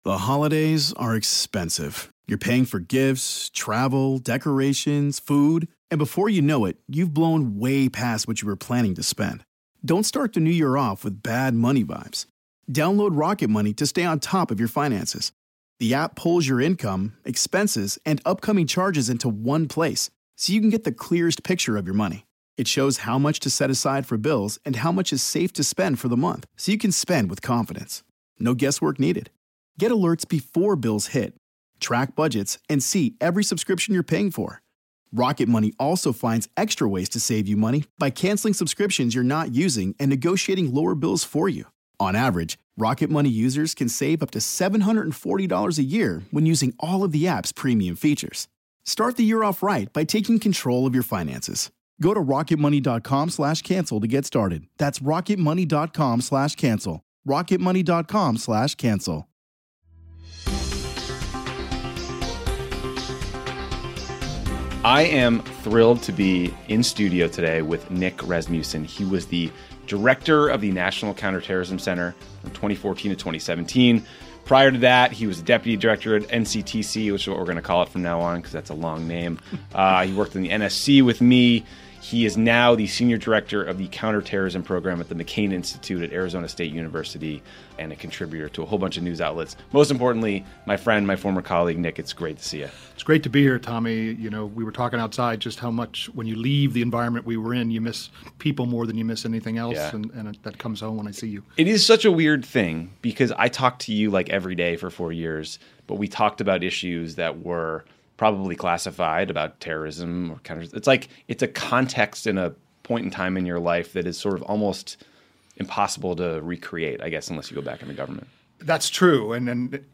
Tommy talks with the outgoing National Counterterrorism Center chief Nick Rasmussen. Nick worked in senior counterterrorism roles for Presidents Bush, Obama and Trump, and they discussed how weird the transition from Obama to Trump was, how you hunt terrorists, how you fight ISIS propaganda, Trump's North Korea summit and more.